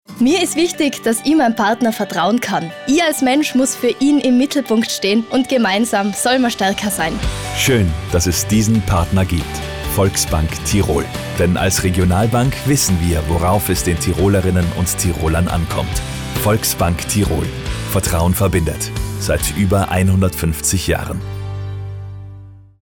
Werbesprecher
Tempo, Melodie, Intensität bestimmen den Klang.
Meine Stimme
SPOT_Volksbank-DUMMY_positiv.mp3